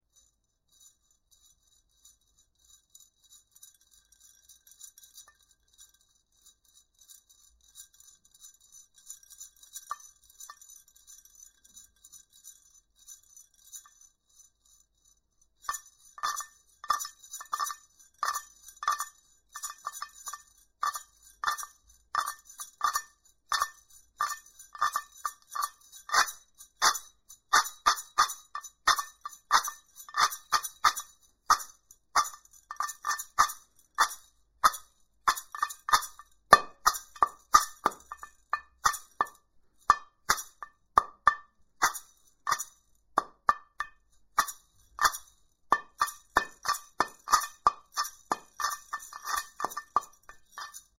Шейкер керамический
Большой керамический шейкер с мембраной из натуральной кожи. Прекрасный перкуссионный инструмент, издаёт громкий звук.